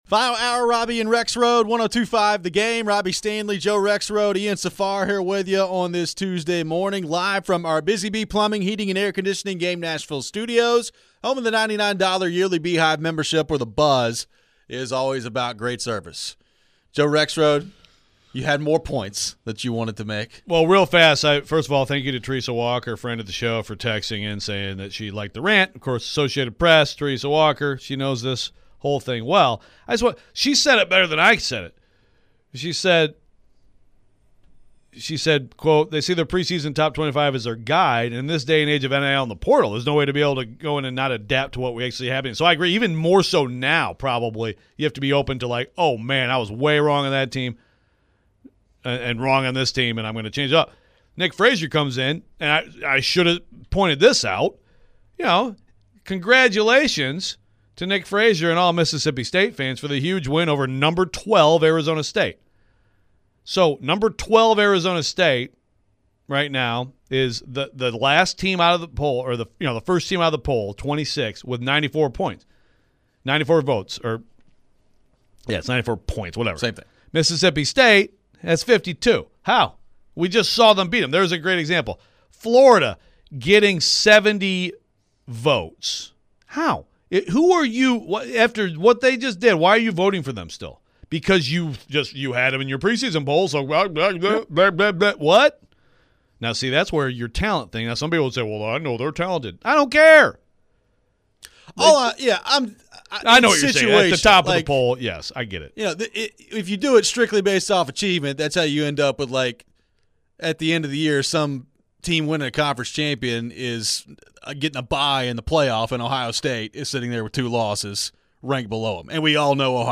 We head back to the phones. Fans want Callahan to stop calling plays.